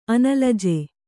♪ analaje